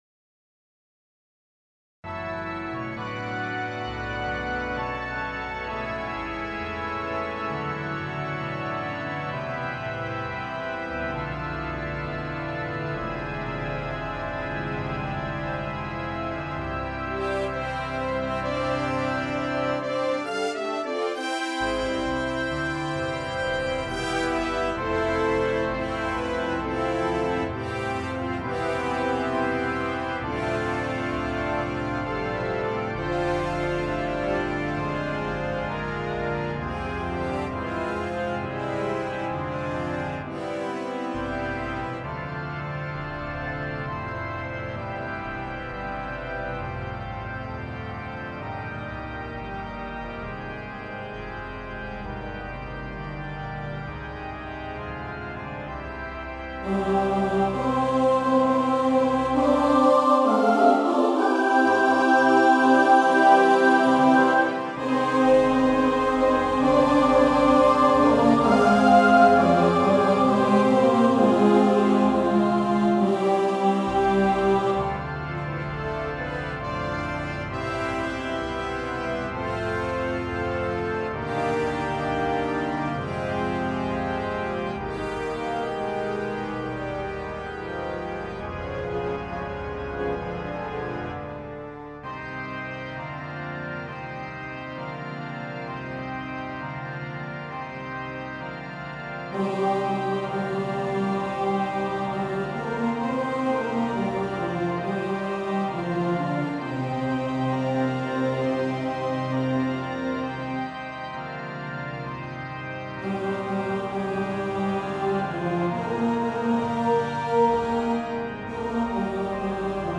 • Accompaniment: Trombone, Trumpet
Using a rising theme